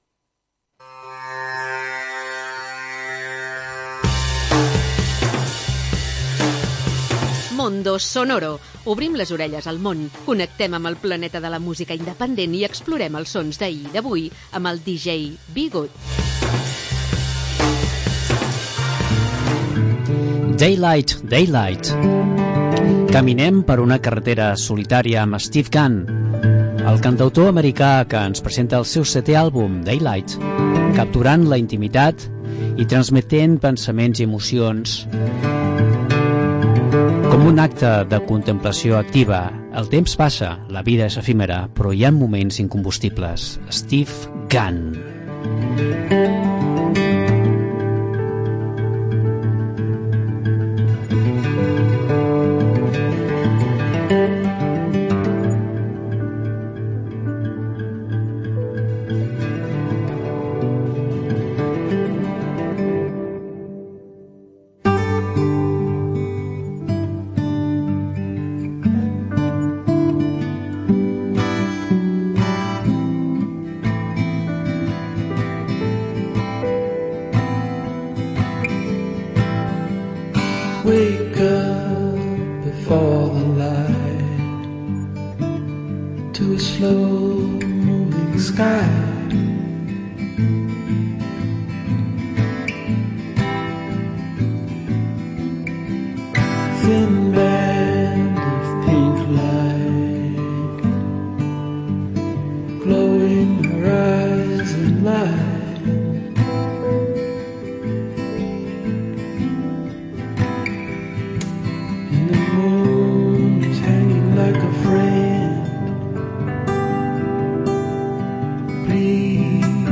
Una selecció de música amb esperit independent